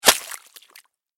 water01gr-converted.mp3